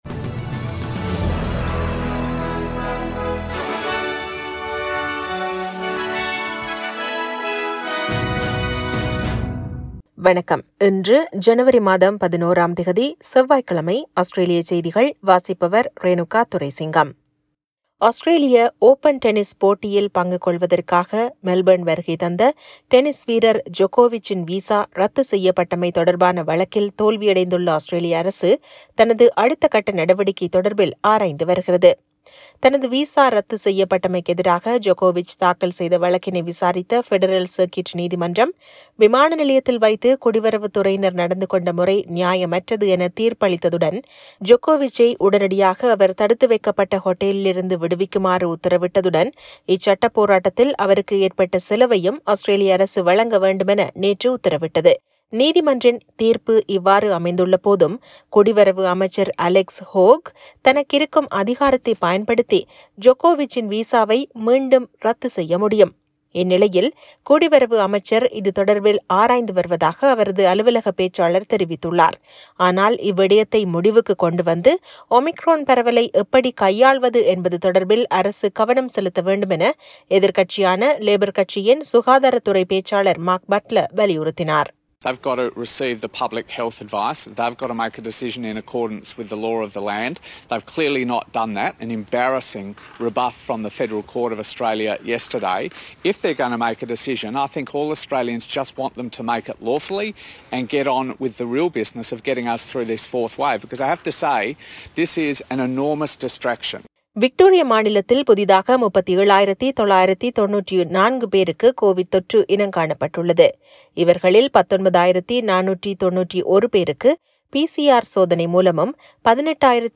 Australian news bulletin for Tuesday 11 Jan 2022.